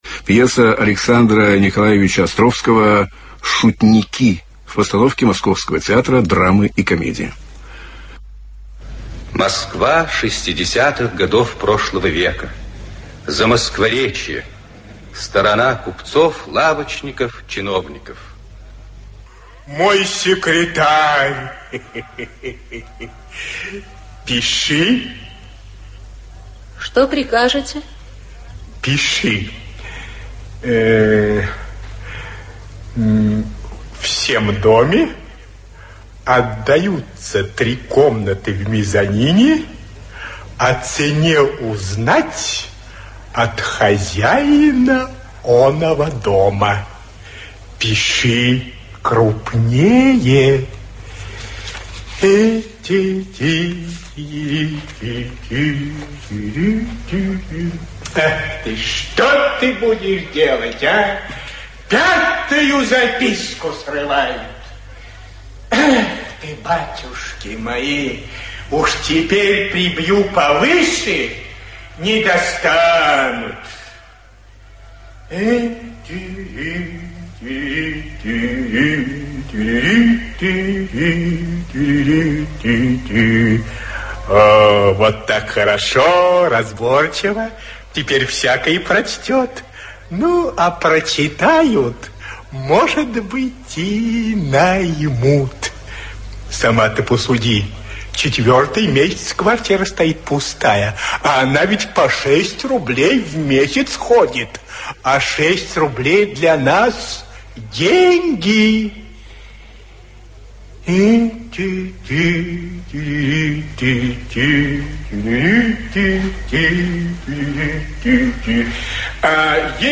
Аудиокнига Шутники. Картины московской жизни (спектакль) | Библиотека аудиокниг